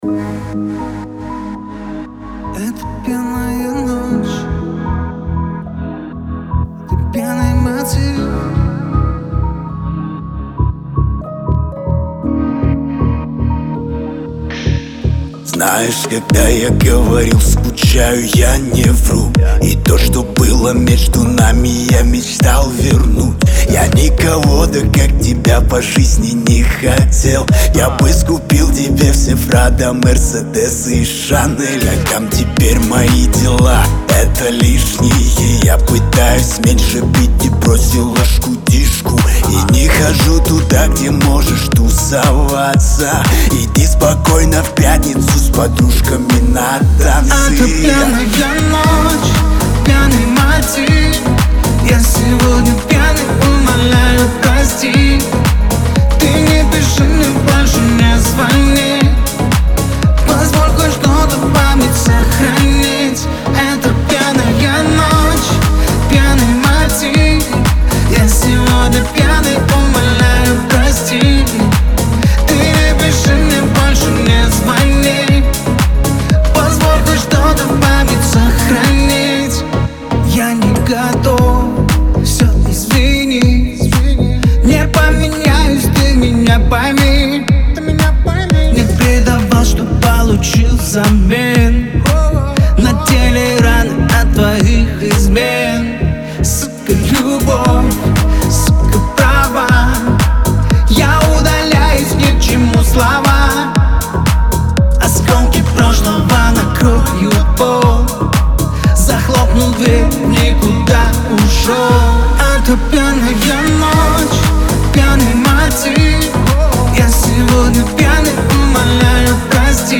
Лирика
грусть , дуэт , ХАУС-РЭП